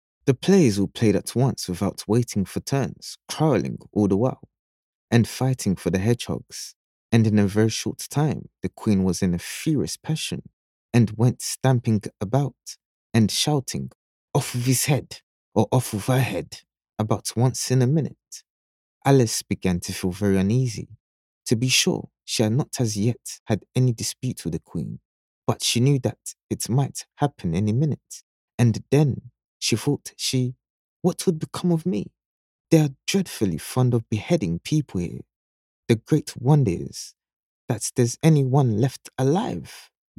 Audio Book Voice Over Narrators
English (Caribbean)
Adult (30-50) | Yng Adult (18-29)